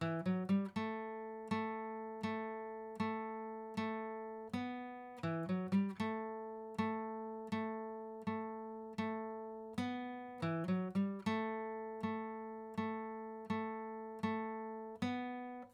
Acoustic guitar
Microphones Used: AKG 411
AKG 451
Master Tempo Track : 120 BPM
acoustic_ptrn_25.wav